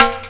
44_10_tom.wav